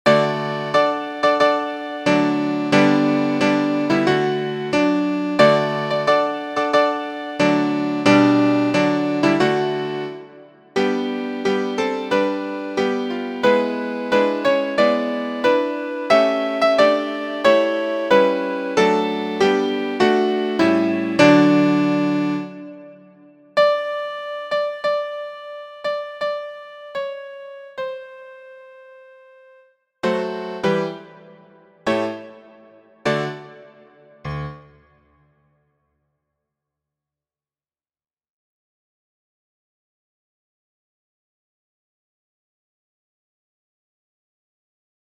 Short, fun, and quite challenging!
Singing octaves leaps and octaves that step down.
• Key: D Major
• Time: 4/4
• Musical Elements: notes: quarter, dotted eighth, eighth, sixteenth; rests: quarter, eighth; lowered 7th (Te), tempo: pomposo/grand dignified, dynamics: forte/loud, mezzo forte/medium loud, fortissimo/very loud, decrescendo; poco ritardando, ad libitum (make-up cockle)